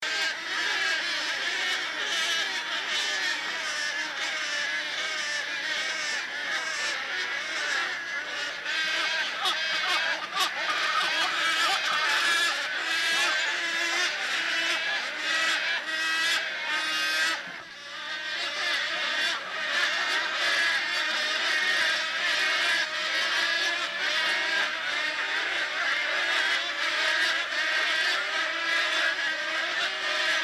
Stork sounds